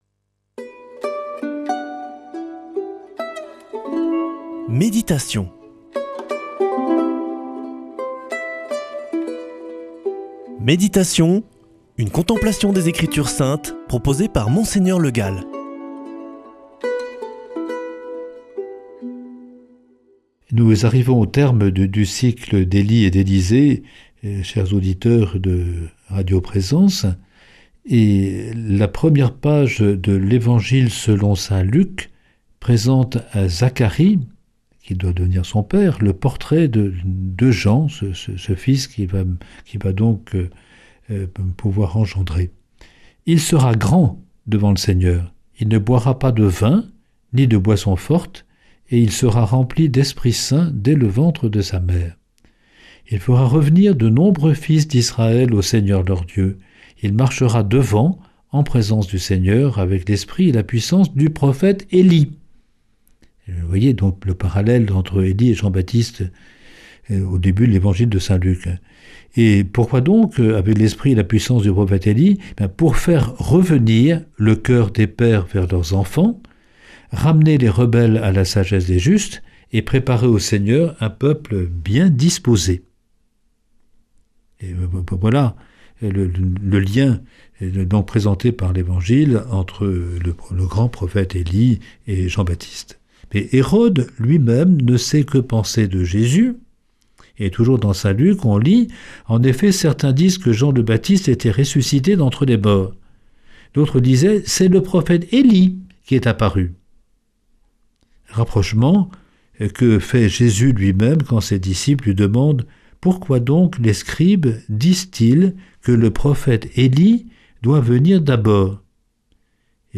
Méditation avec Mgr Le Gall
Une émission présentée par